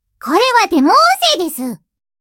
2121-8 / japanese-parler-tts-mini like 7